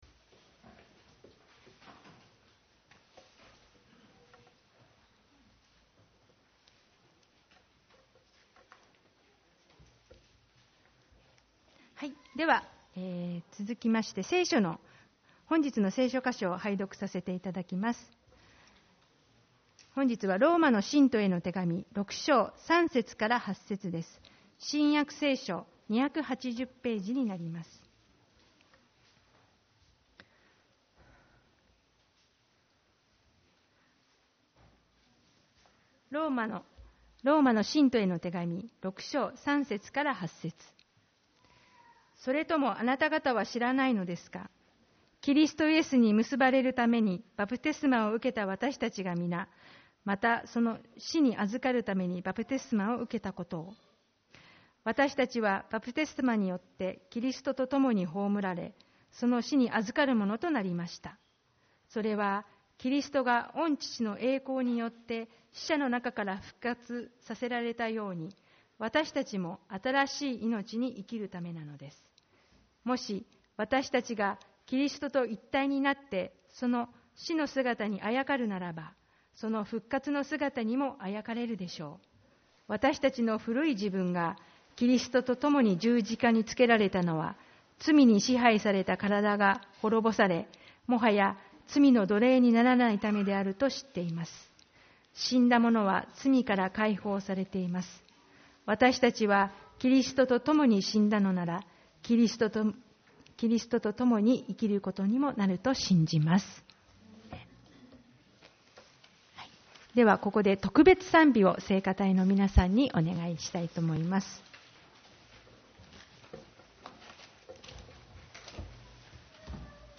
イースター礼拝 「復活の命に生きる」